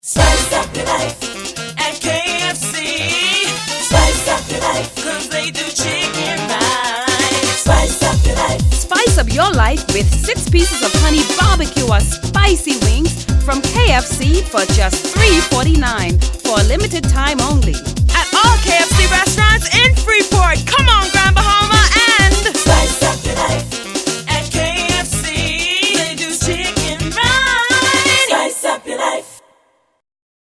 37900 bytes)Jingle Time-
lead vocals